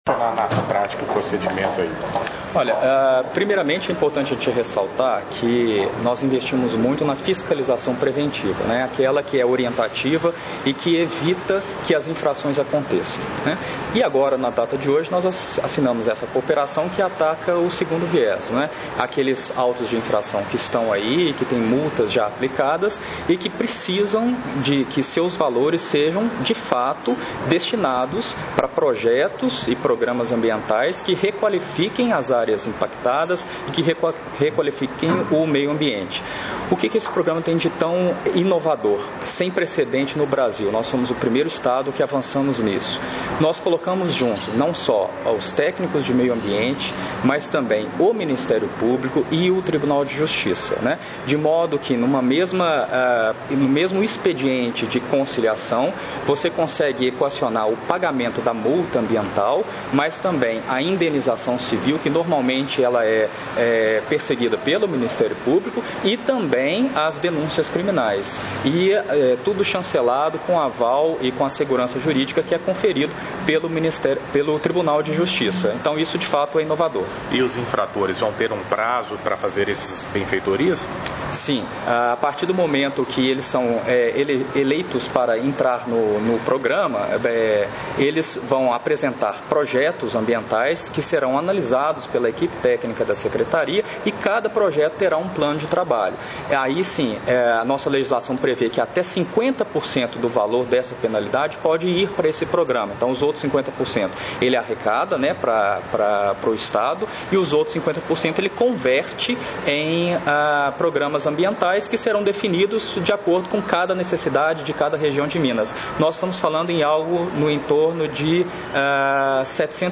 - Ouça o Governador reforçando a iniciativa